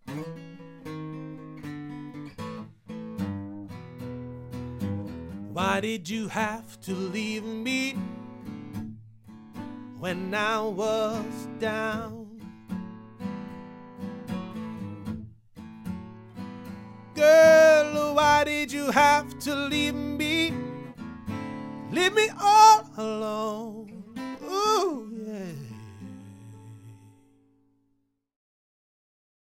オーディオ・デモ
近接効果のない原音に忠実で透明なサウンド
ドラム・オーバーヘッド
アコースティック・ギター